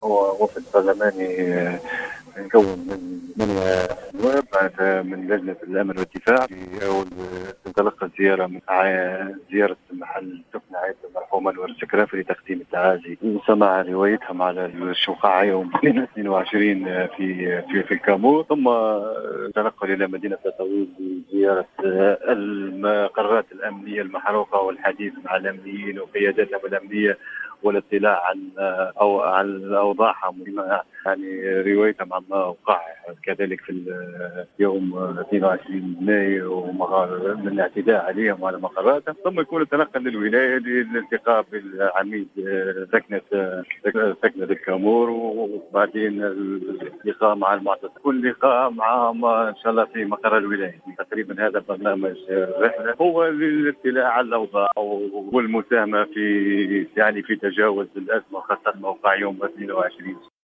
Le député d’Ennahdha à Tataouine, Bechir Khelifi, a annoncé ce lundi 5 juin 2017 sur les ondes de Jawhara FM qu’une délégation parlementaire de 13 députés et membres de la commission de sécurité et de défenseà l’Assemblée des représentants du peuple (ARP) effectue aujourd’hui une visite de travail à Tataouine.